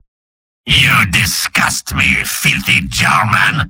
Robot-filtered lines from MvM.
{{AudioTF2}} Category:Spy Robot audio responses You cannot overwrite this file.